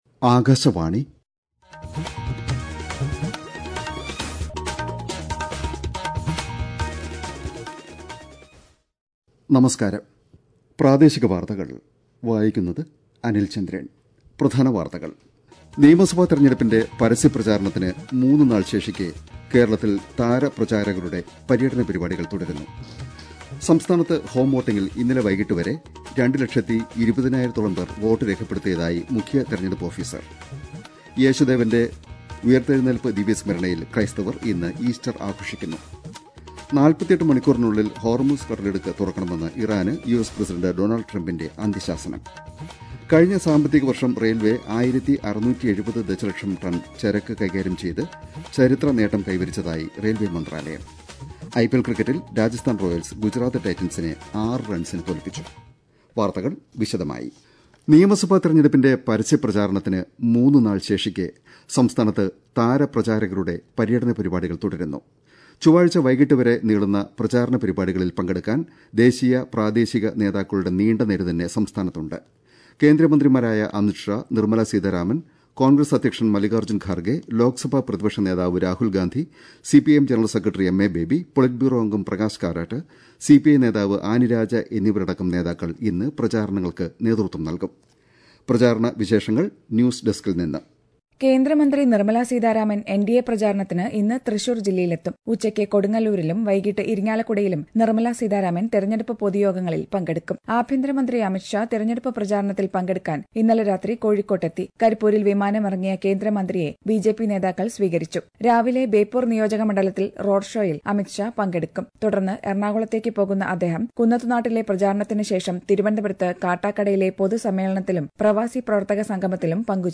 Access Bulletins From Cities